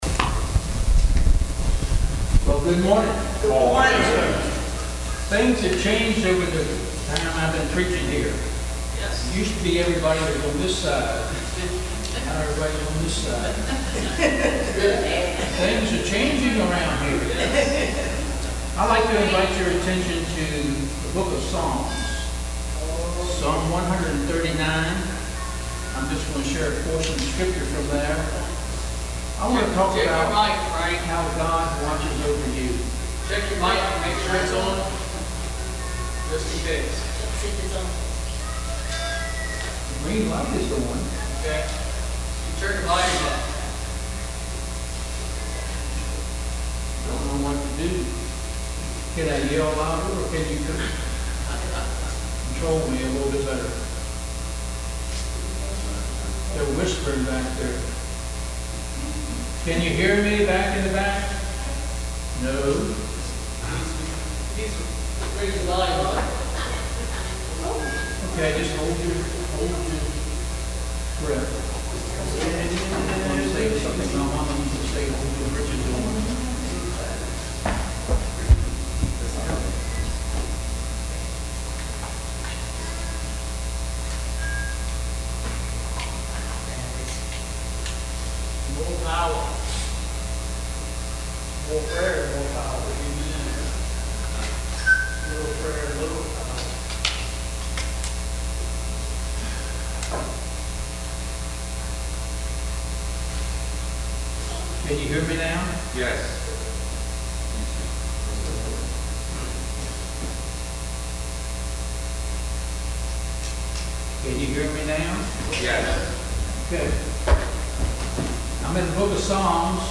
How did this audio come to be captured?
Sermons preached at Salem Baptist Church in Richmond, VA.